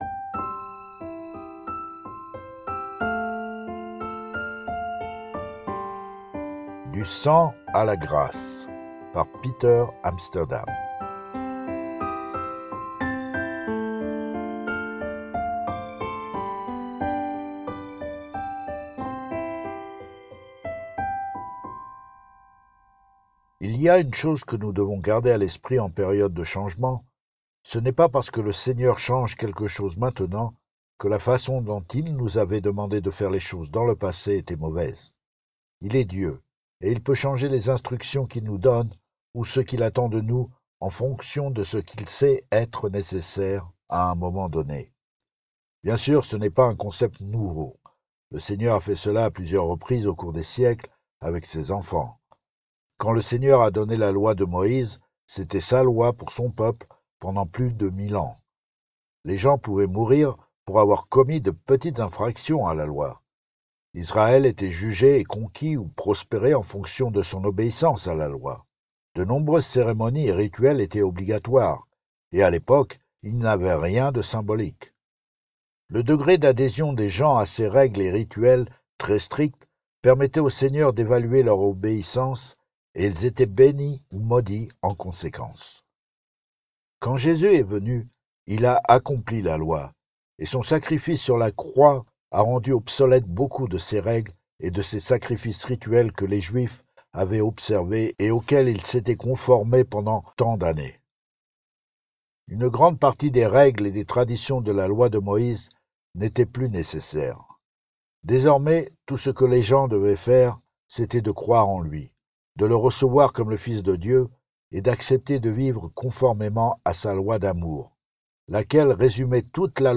Lu par